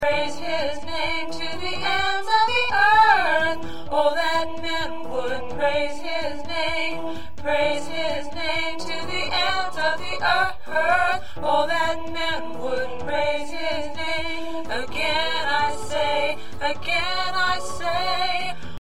Practice tracks are based on the sheet music.